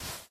sand4.ogg